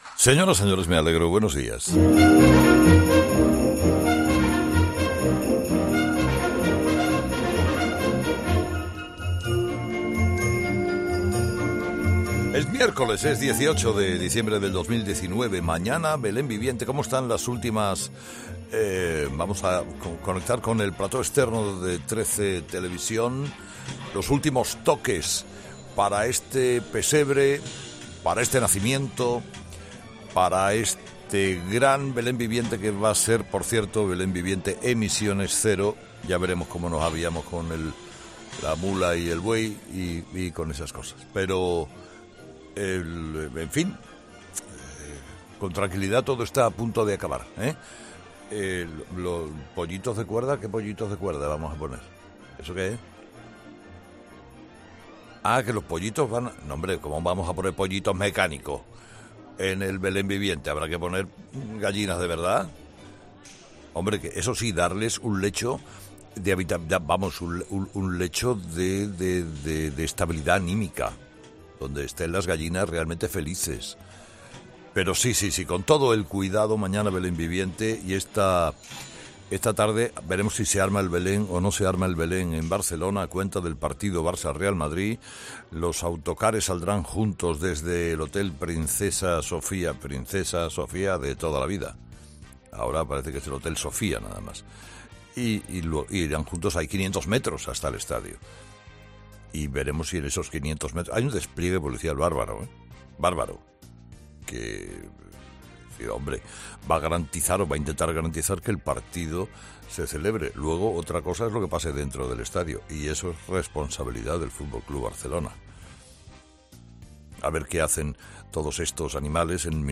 ESCUCHA AQUÍ EL EDITORIAL DE HERRERA Y está esta tarde veremos si se arma el Belén o no se arma el Belén en Barcelona a cuenta del partido Barça-Real Madrid.